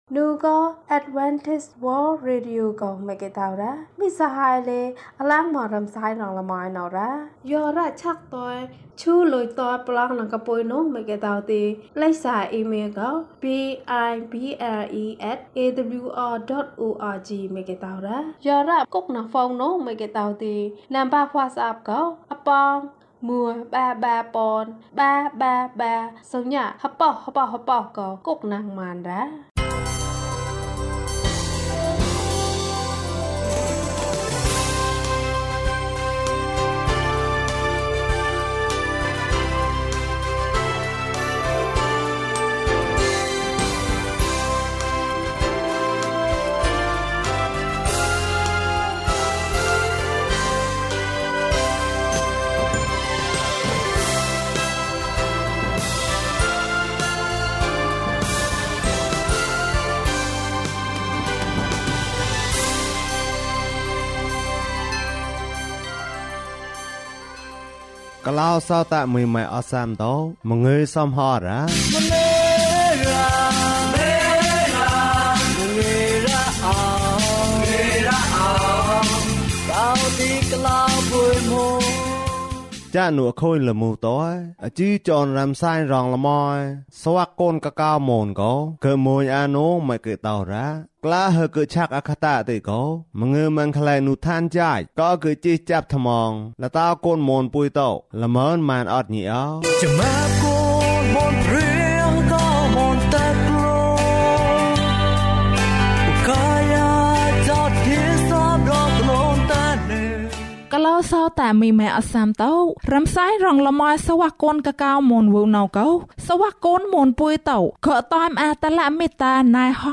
နေရောင်ခြည်ကို ငါမြင်တယ်။ ကျန်းမာခြင်းအကြောင်းအရာ။ ဓမ္မသီချင်း။ တရားဒေသနာ။